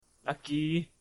「喜」のタグ一覧
ボイス
男性